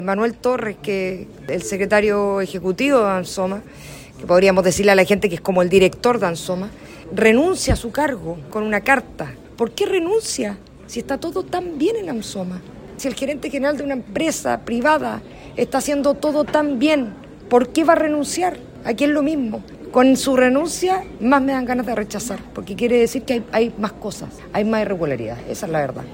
La concejala de la derecha, Micaela Becker, cuestionó que una persona renuncie cuando en el municipio se busca instalar el mensaje de que en Amzoma todo está bien.